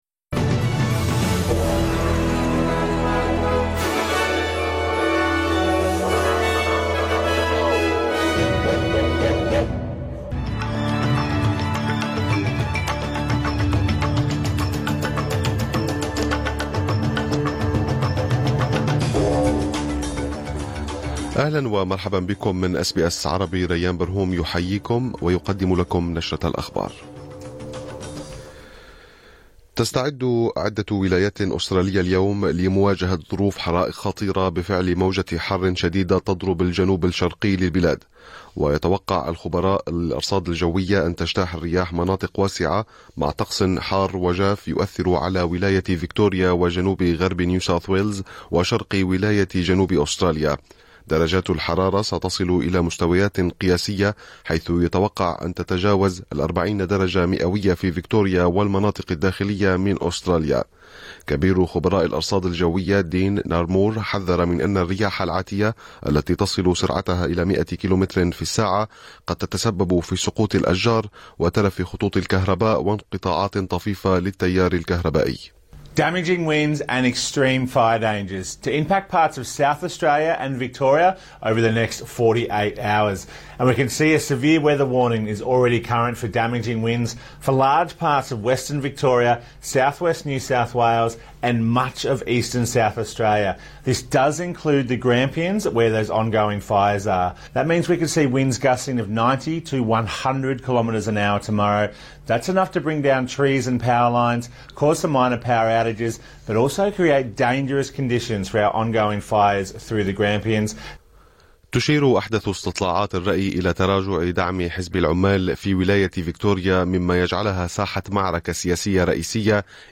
نشرة أخبار الظهيرة 26/12/2024